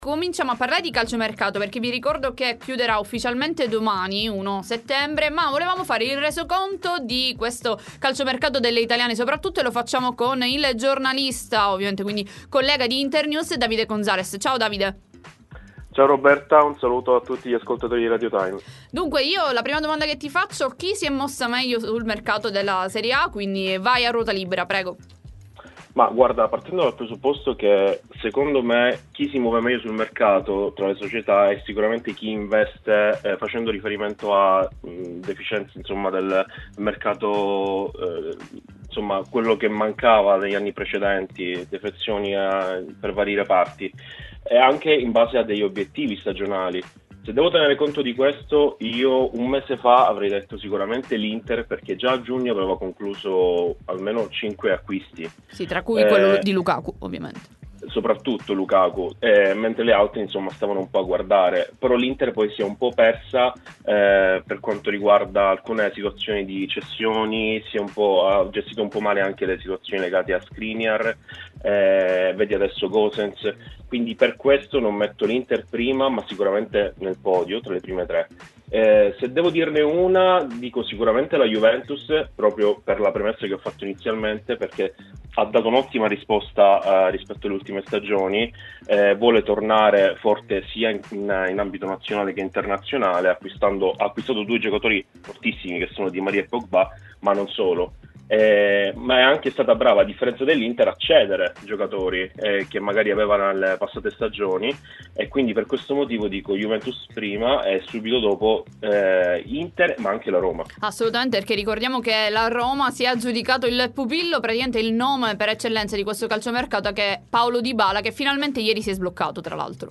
Doc Time intervista Time Magazine